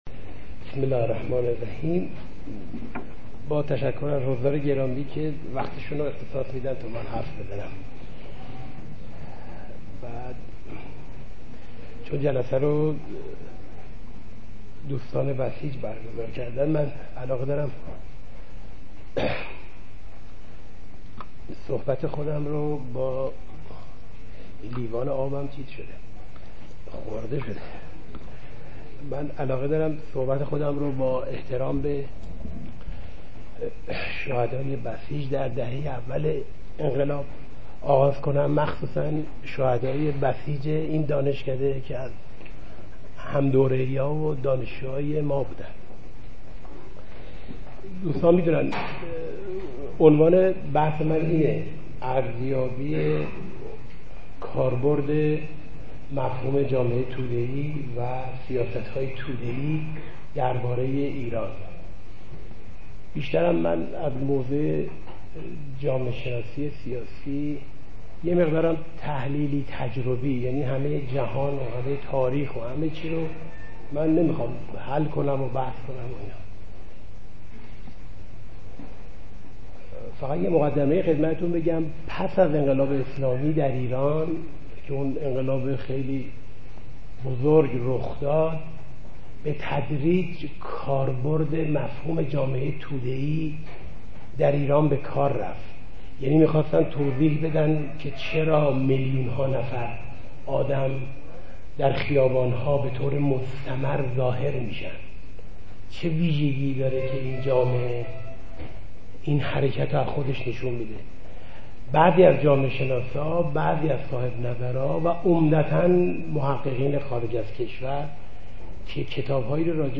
سخنرانی
می باشد که در همایش پوپولیسم؛ عوام گرایی یا مردم باوری در دانشکده علوم اجتماعی دانشگاه تهران در تاریخ ۵/۱۰/۱۳۸۵ ایراد شده است.